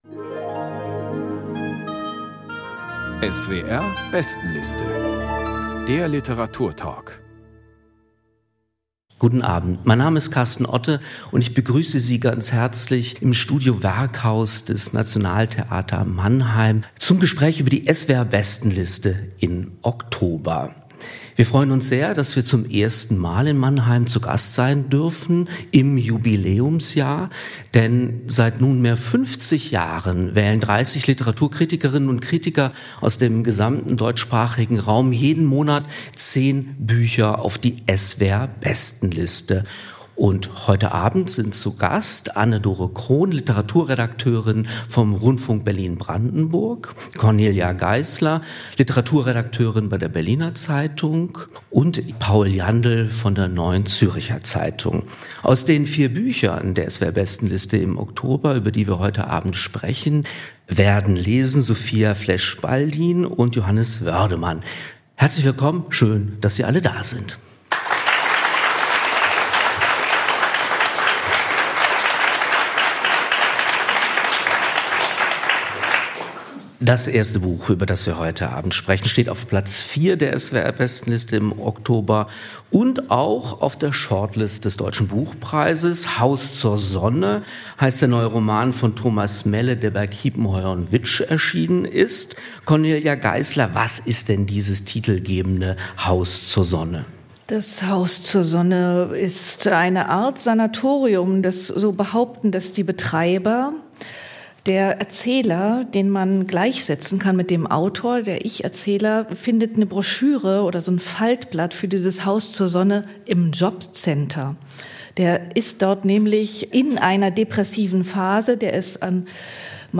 Beschreibung vor 6 Monaten Eine Premiere im Jubiläumsjahr: Die SWR Bestenliste gastierte zum ersten Mal im Studio Werkhaus des Mannheimer Nationaltheaters.